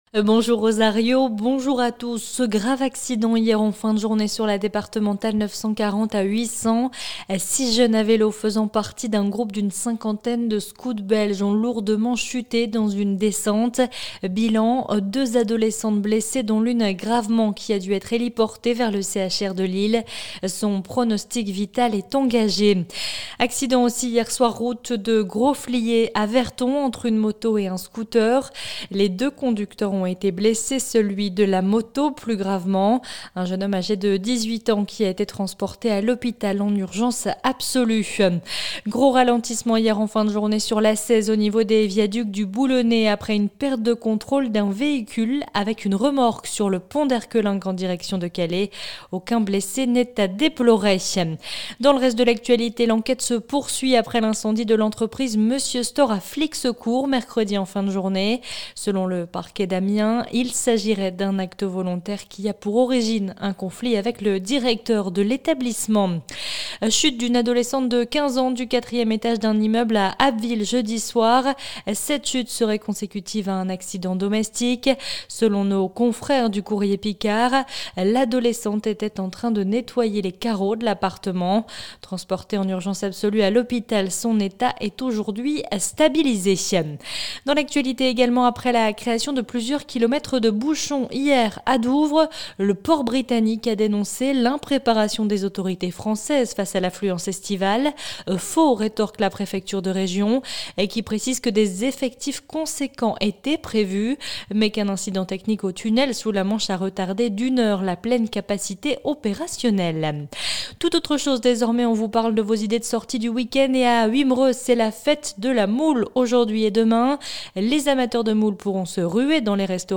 Retrouvez le journal de la Côte d'Opale et de la Côte Picarde en 5 minutes